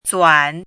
怎么读
zuǎn
zuan3.mp3